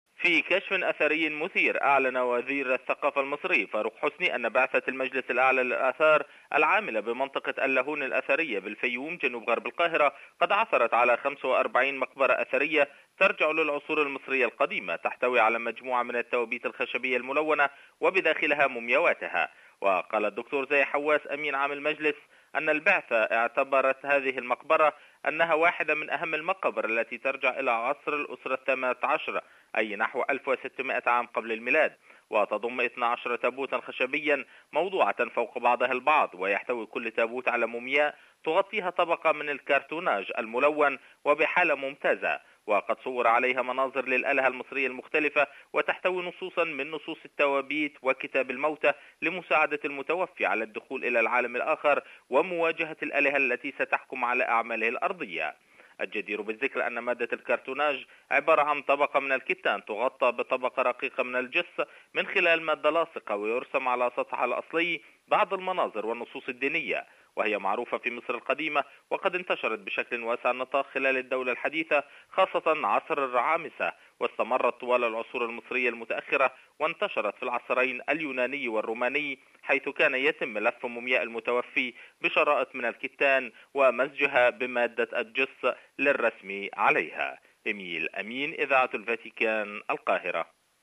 أعلن مجلس الآثار المصري الأعلى عن العثور على 45 مقبرة اثرية تعود للعصور المصرية القديمة في محافظة الفيوم جنوب القاهرة. التفاصيل في تقرير مراسلنا في القاهرة: